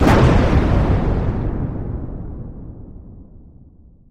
PLAY Explosiion
explosiion.mp3